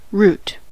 root: Wikimedia Commons US English Pronunciations
En-us-root.WAV